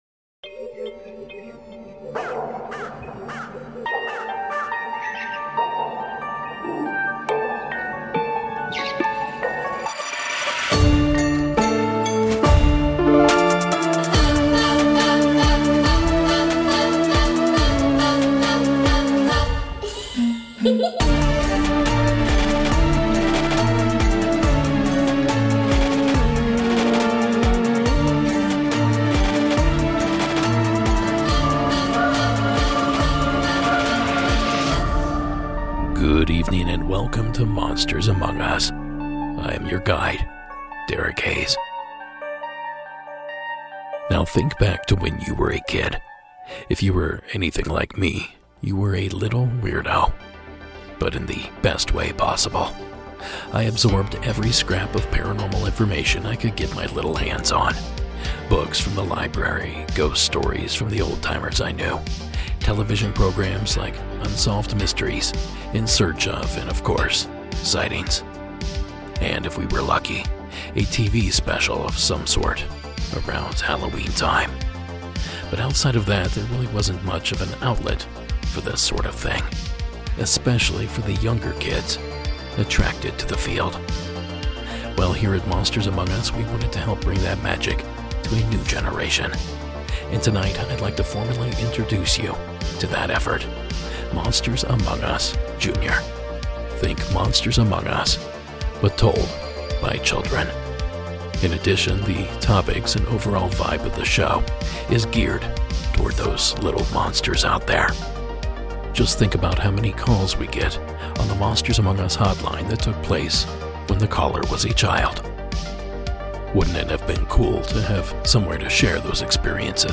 Think: Monsters Among Us, but the stories are told by children, and the topics and overall vibe of the show is geared toward all the little monsters out there.